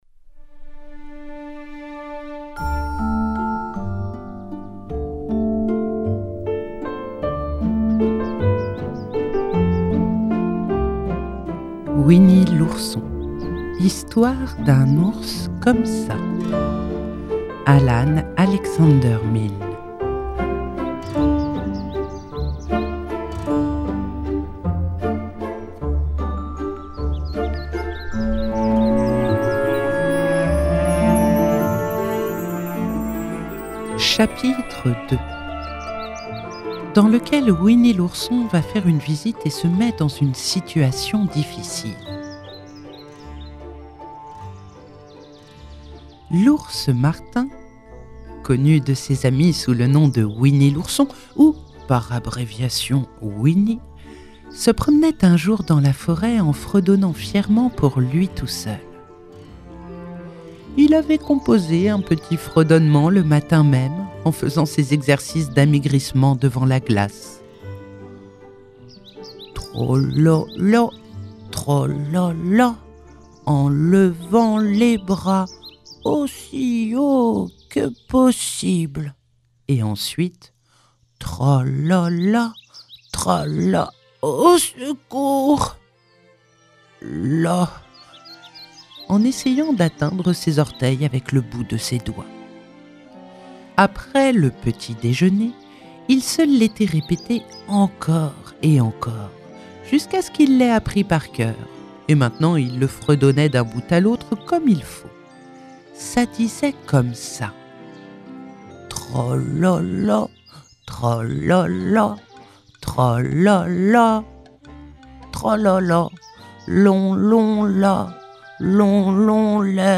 🎧 Winnie l’Ourson – Alan Alexander Milne - Radiobook